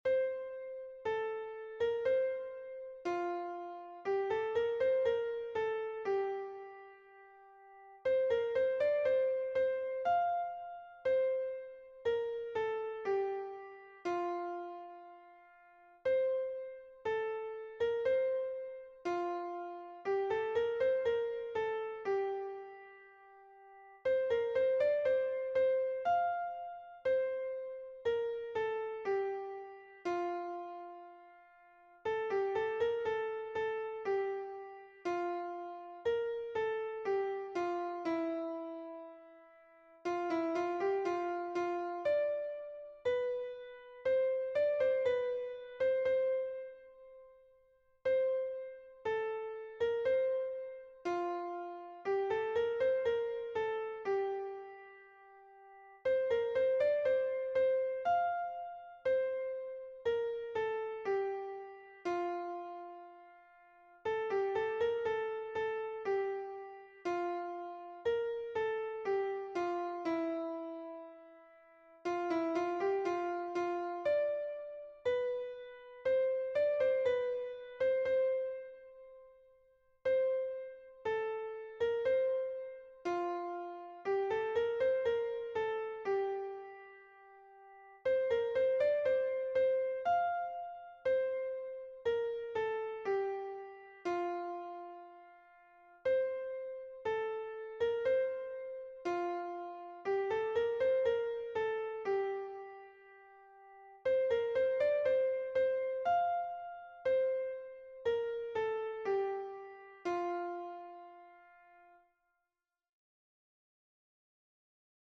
- Œuvre pour chœur à 4 voix mixtes (SATB)
Soprano